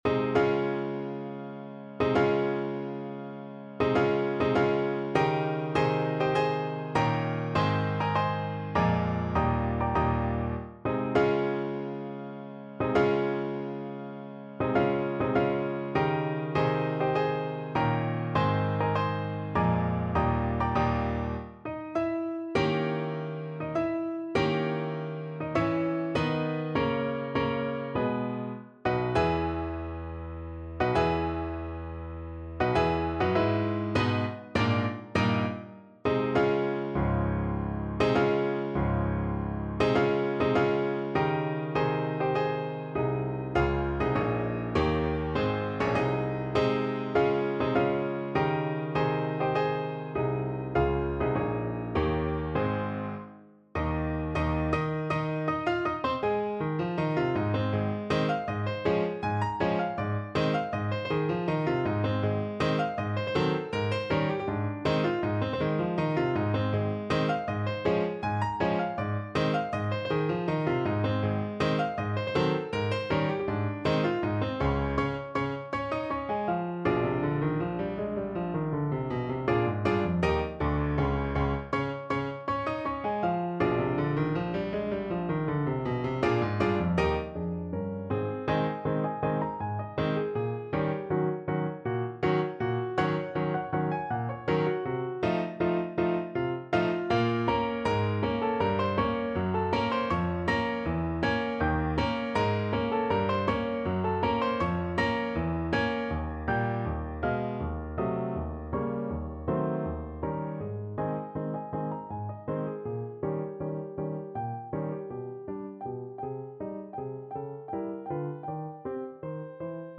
No parts available for this pieces as it is for solo piano.
3/4 (View more 3/4 Music)
Classical (View more Classical Piano Music)